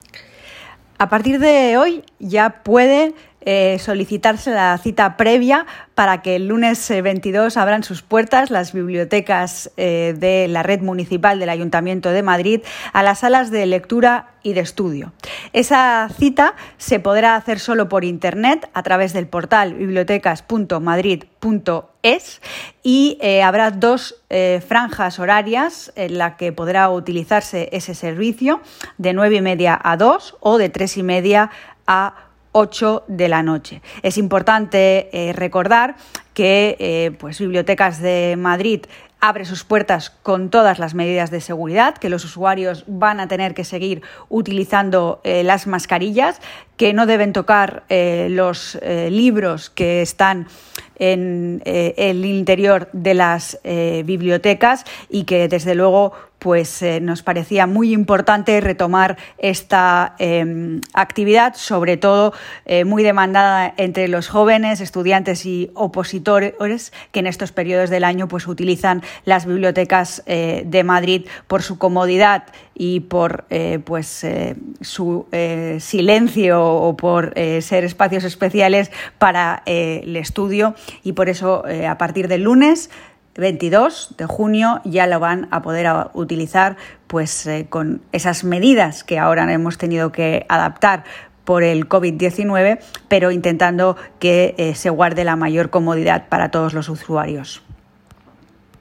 Nueva ventana:Andrea Levy explica cómo solicitar la cita previa y las normas de uso para poder acceder a estas salas de estudio